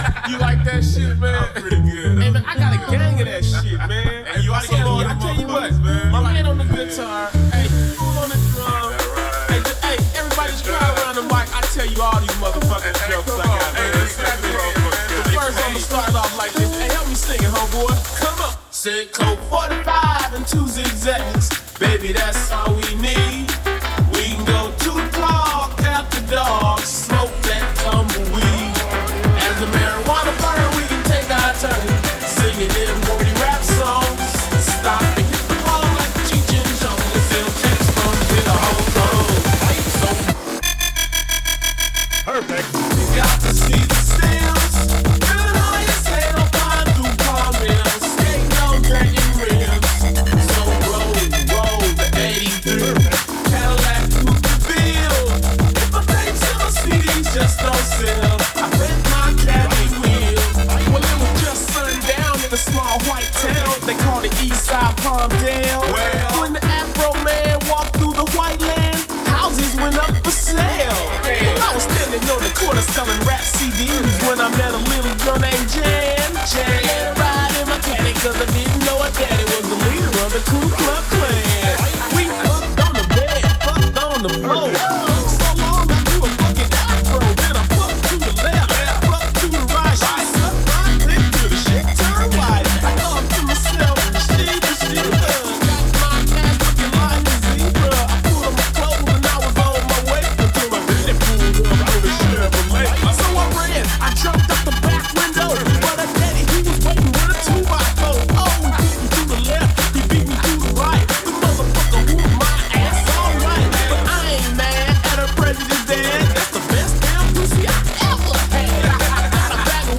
rough drafts of musical blends